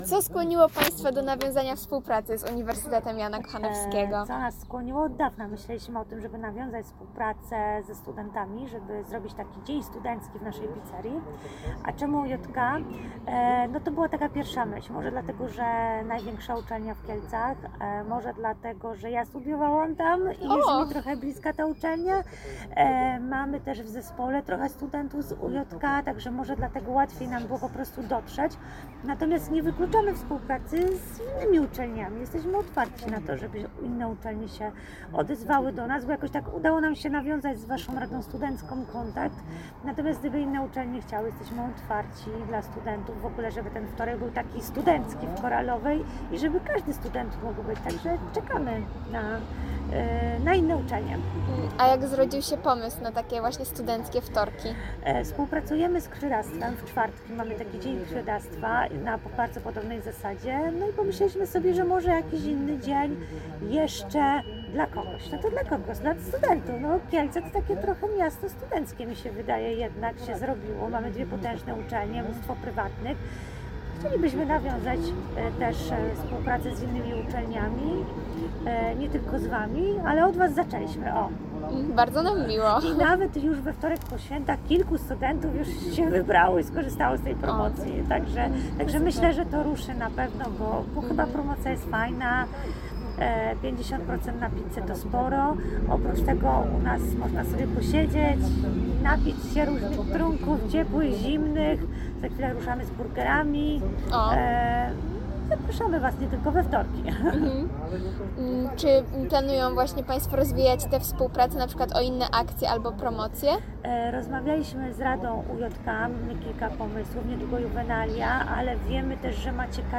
Pizzeria-Koralowa-krotki-wywiad.mp3